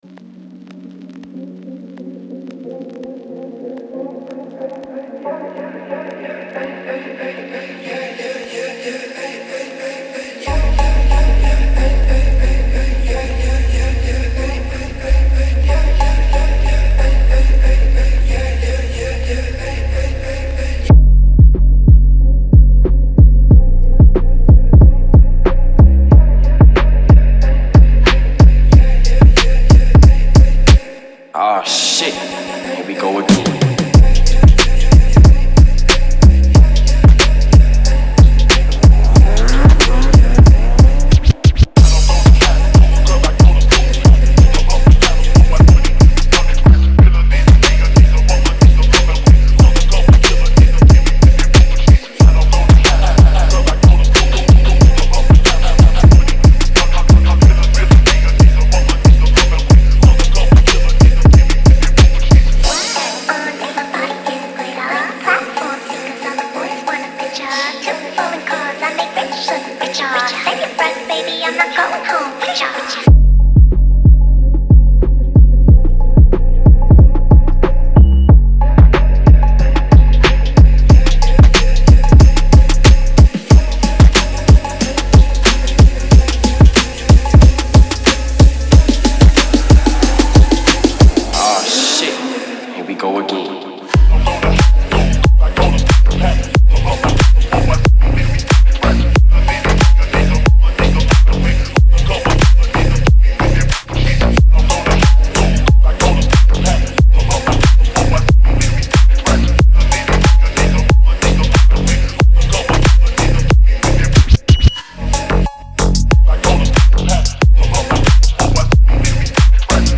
БАСЫ в МАШИНУ